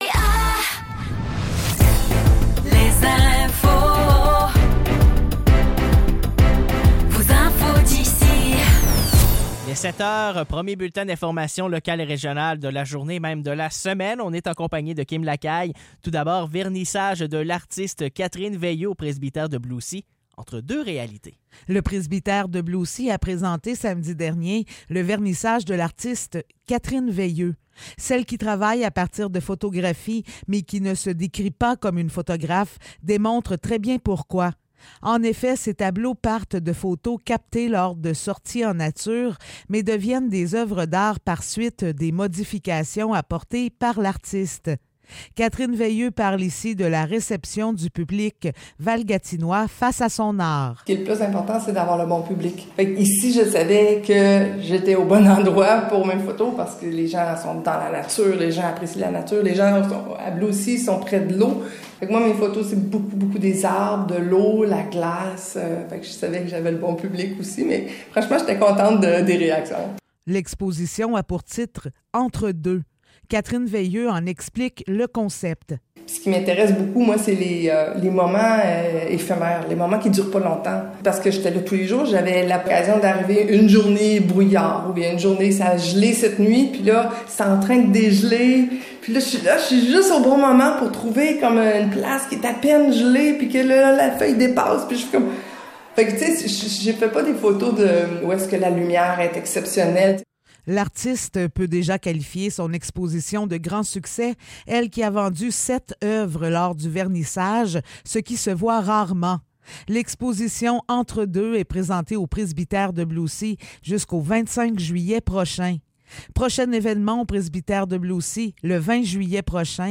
Nouvelles locales - 8 juillet 2024 - 7 h